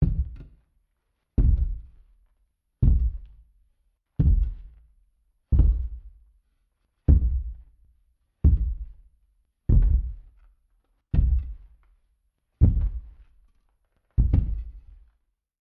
Звуки великанов
Здесь собраны аудиозаписи, передающие шаги, голоса и атмосферу этих гигантских существ.